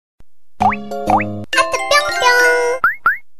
MP3铃声